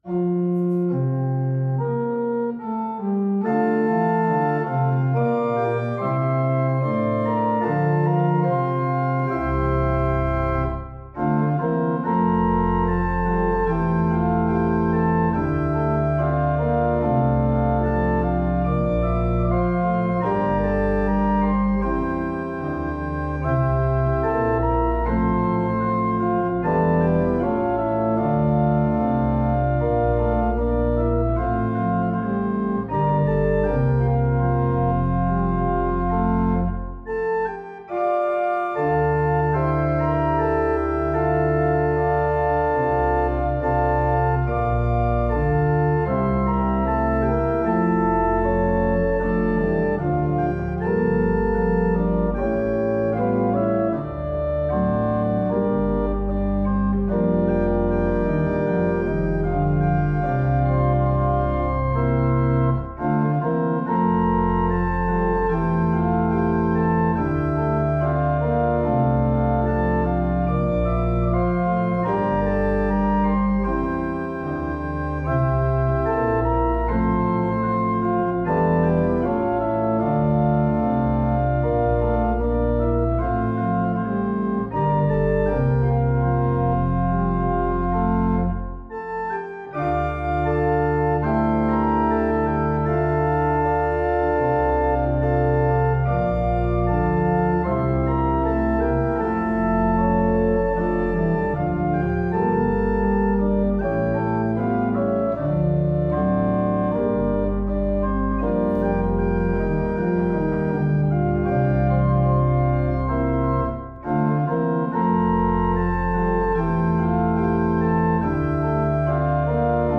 énekszöveg nélkül, csak zene, amire énekelni lehet: